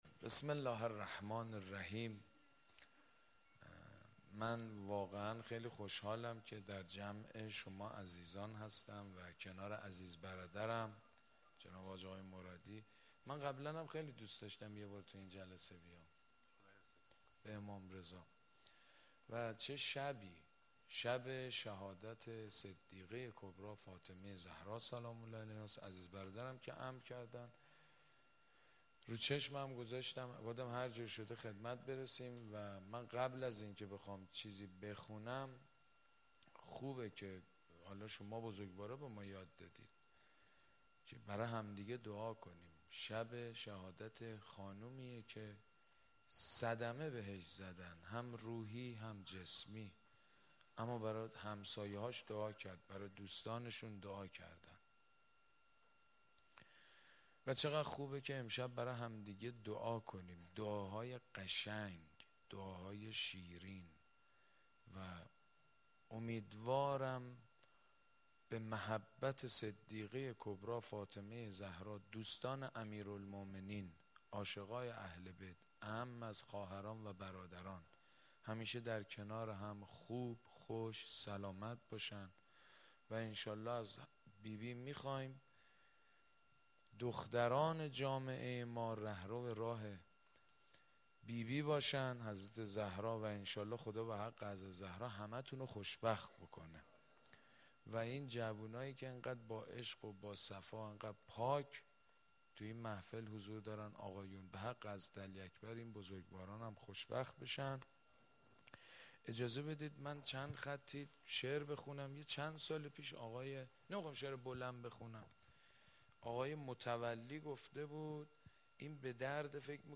آخرین جلسه دوره چهارم کلاس مجرد ها در ایوان شمس یک میهمان ویژه داشت . حاج سید مجید بنی فاطمه مداح و ذاکر اهل بیت(ع) به مناسبت ایام فاطمیه با حضور در این جلسه دقایقی به مداحی و روضه خوانی پرداخت.